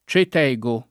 vai all'elenco alfabetico delle voci ingrandisci il carattere 100% rimpicciolisci il carattere stampa invia tramite posta elettronica codividi su Facebook Cetego [ © et $g o ] pers. m. stor. — pl. -gi , come nome della gente romana